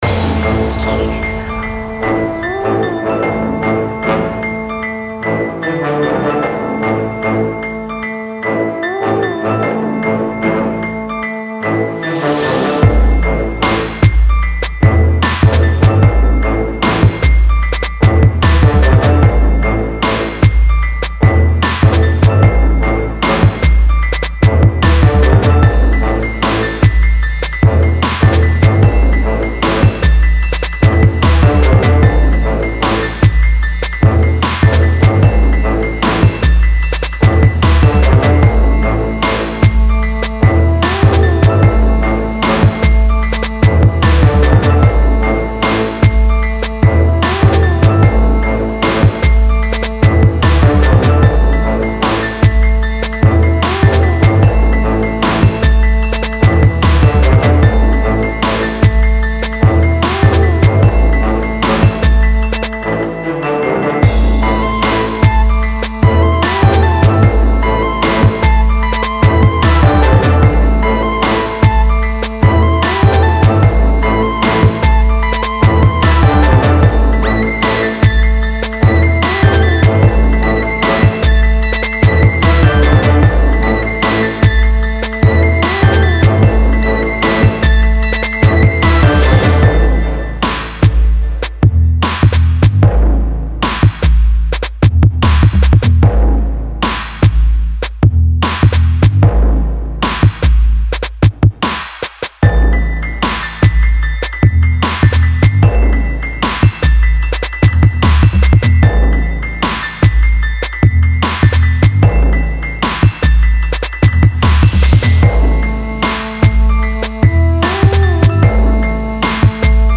Нужна критика (Rap\Rnb)